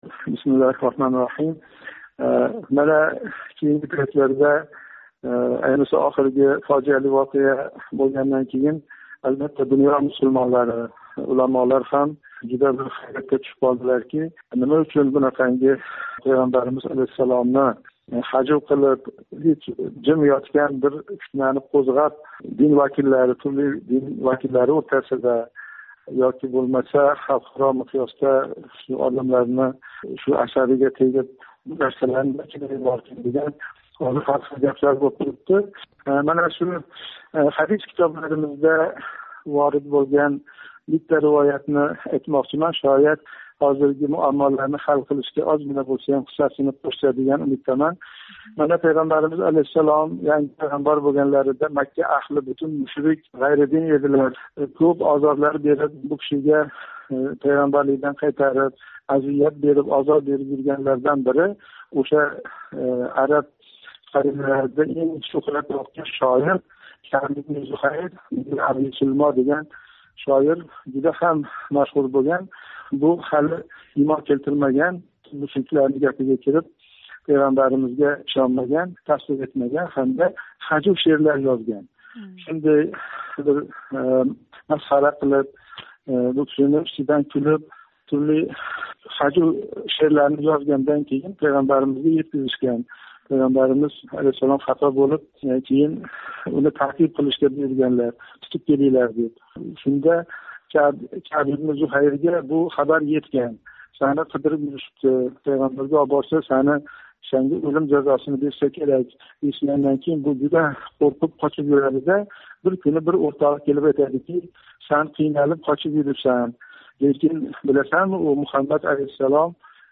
Абдулазиз Мансур билан суҳбат